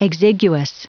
Prononciation du mot exiguous en anglais (fichier audio)
Prononciation du mot : exiguous